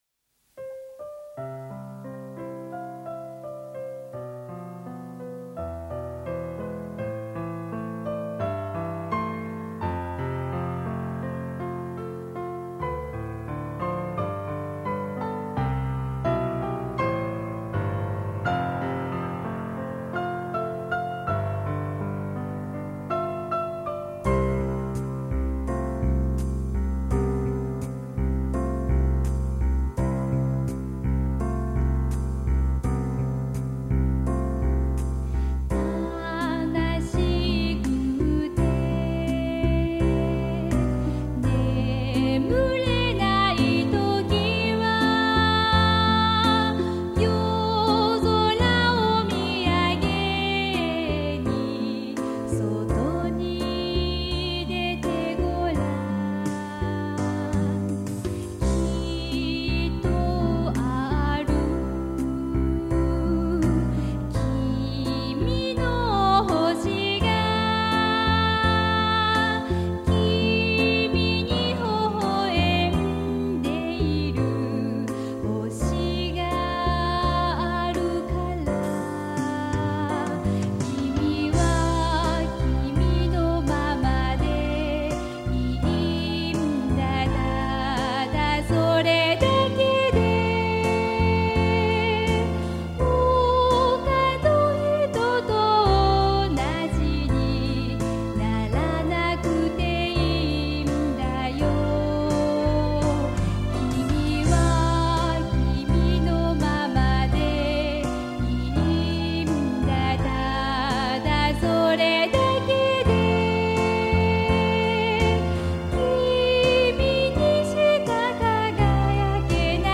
カラオケ・バージョンなのだそうです。新しいアレンジで、前回よりずっと音も厚みがあり、豊かになったように思います。